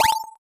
purchase.mp3